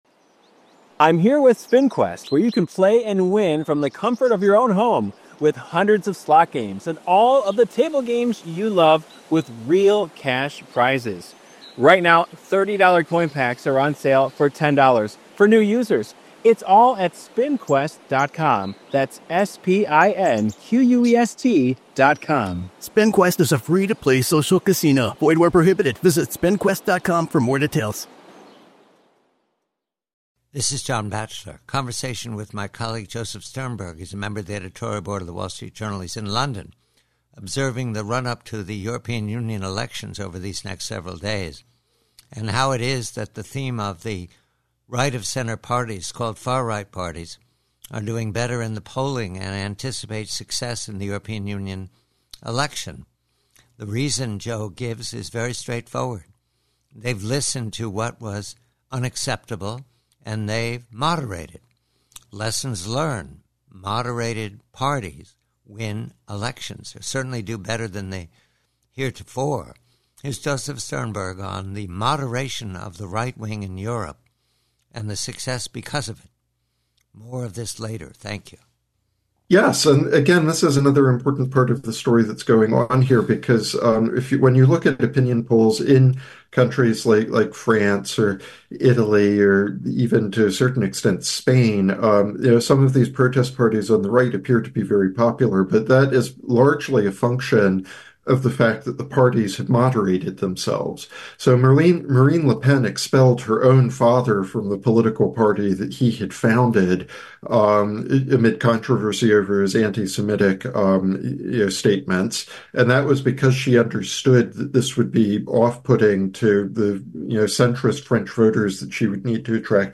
PREVIEW: EU: Conversation